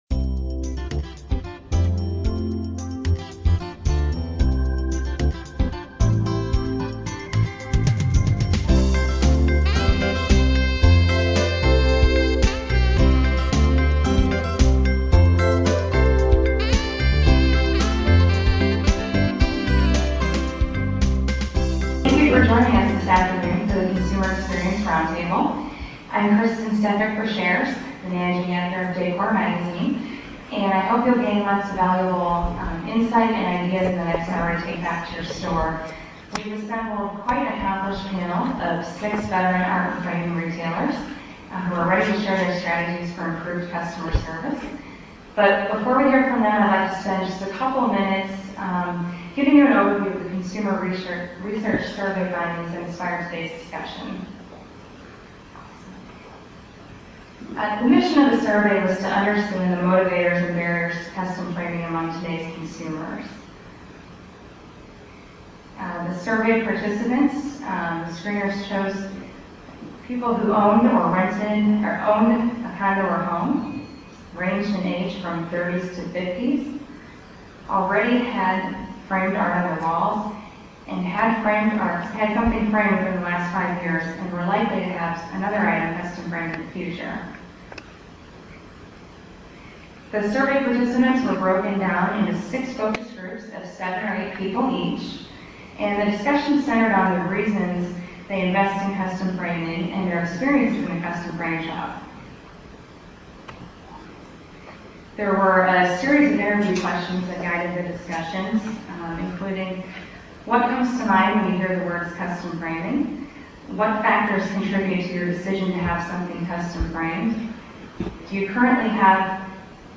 Retail Customer Experience Panel Discussion
This led art and framing industry magazine Decor Magazine to conduct original research using small focus groups. At the recent Decor Expo trade show, the magazine invited a panel of experts to comment on these findings.
An extremely lively discussion ensued.